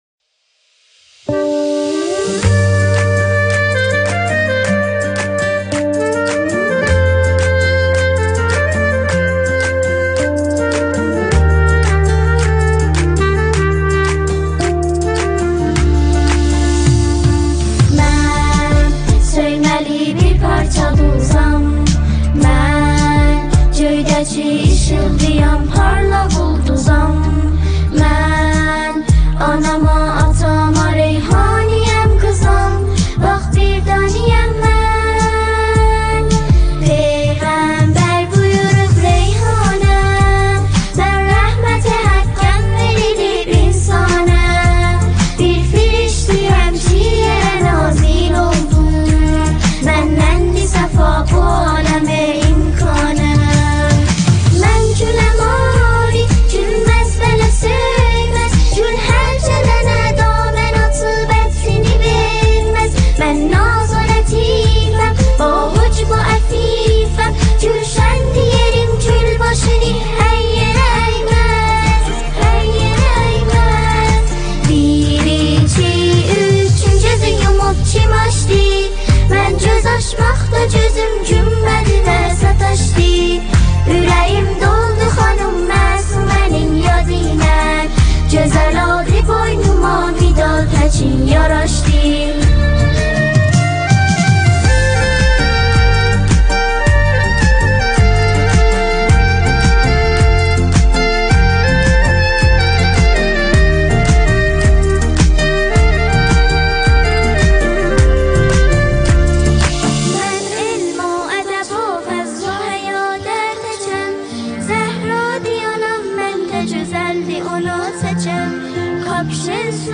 نماهنگ زیبای آذری «ریحانه» با صدای گروه سرود نسل شهید سلیمانی، ویژه سالروز میلاد حضرت فاطمه معصومه سلام الله علیها و روز دختر / مدت : 3 دقیقه